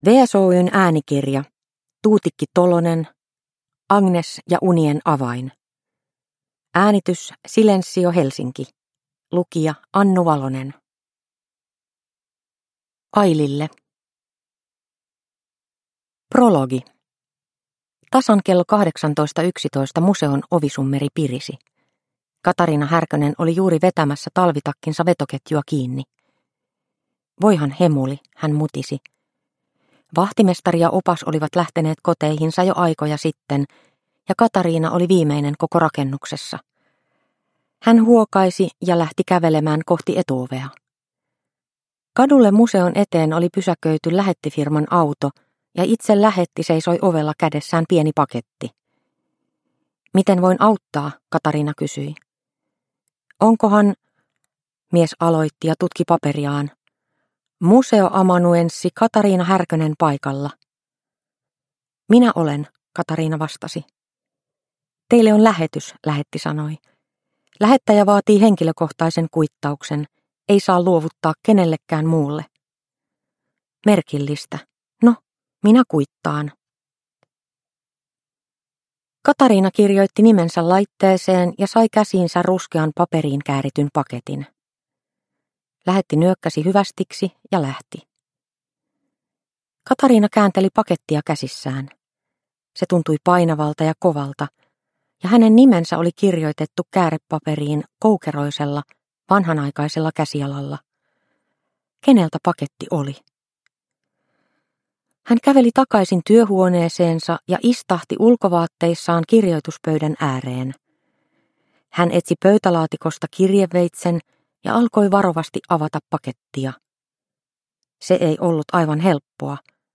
Agnes ja unien avain – Ljudbok – Laddas ner